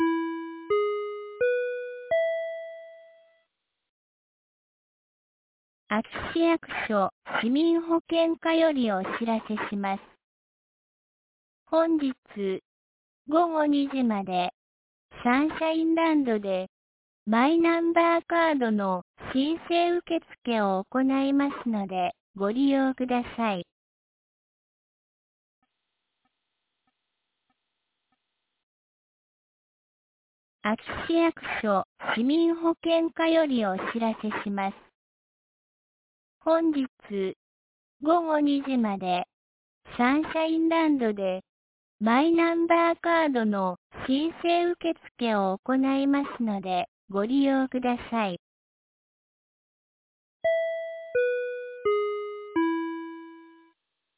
2024年10月19日 12時15分に、安芸市より全地区へ放送がありました。
放送音声